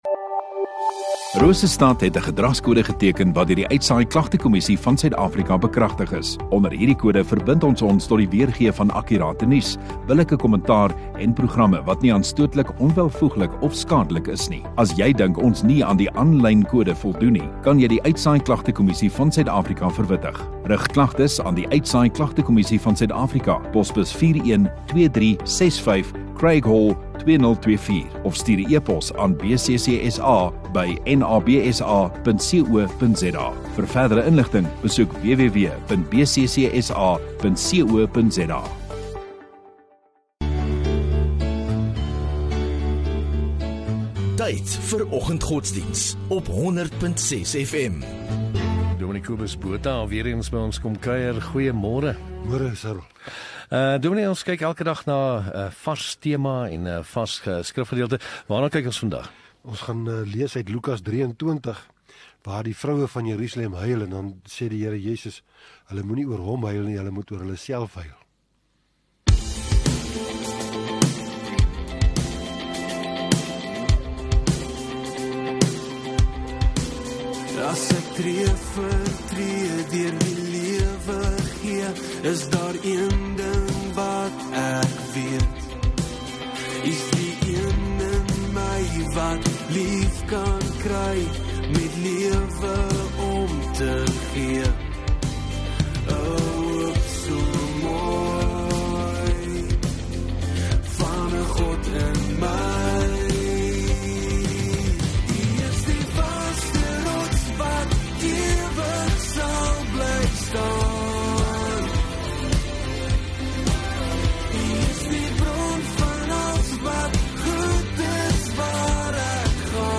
18 Apr Donderdag Oggendddiens